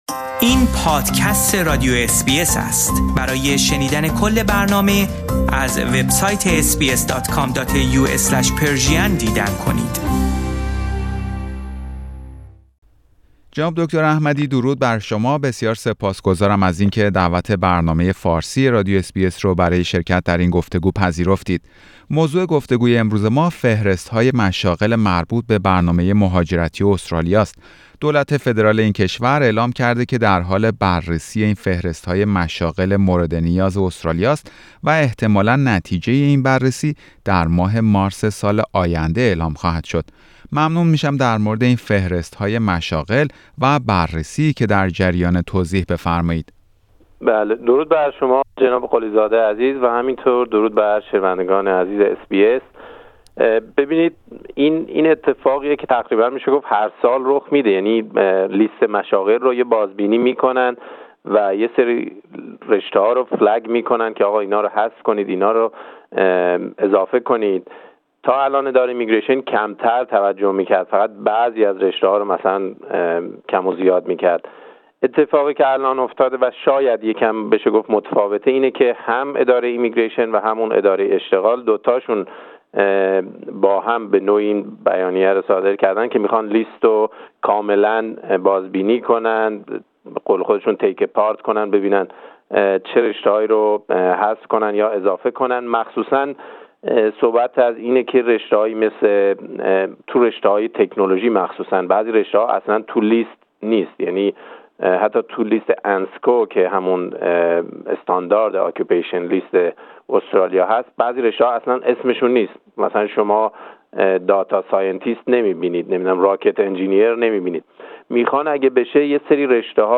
در همین خصوص، برنامه فارسی رادیو اس بی اس گفتگویی داشته است